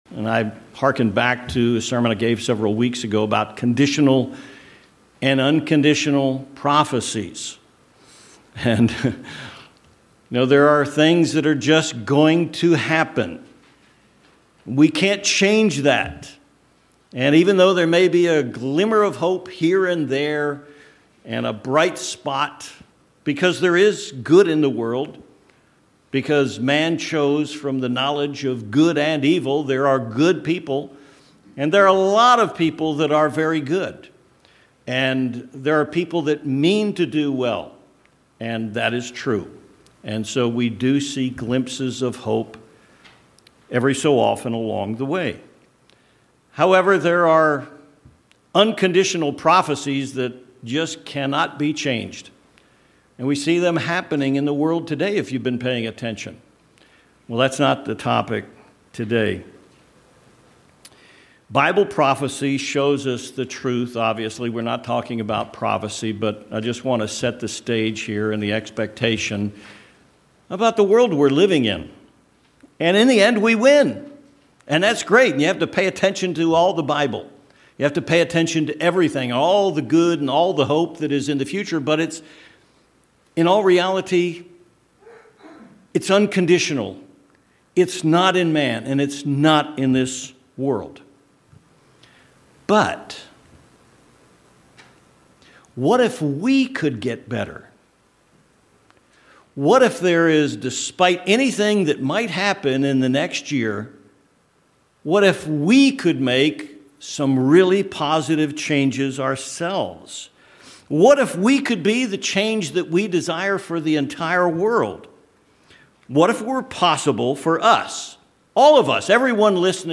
But within our control is our ability to learn and grow. This sermon shows a simple plan for growing in wisdom for the next calendar year.